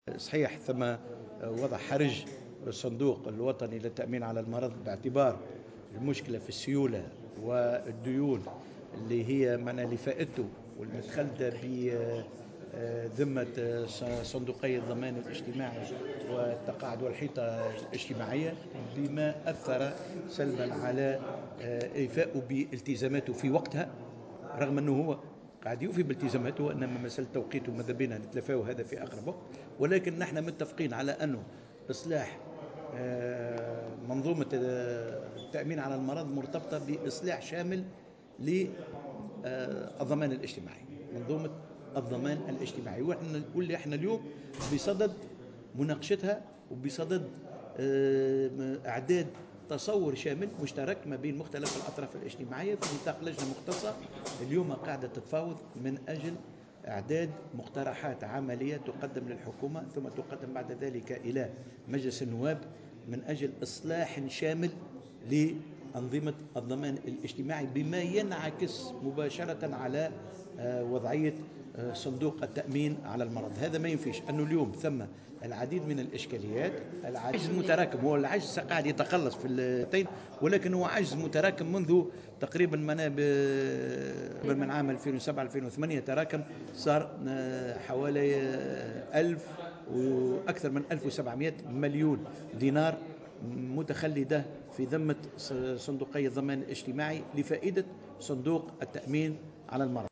وزير الشؤون الاجتماعية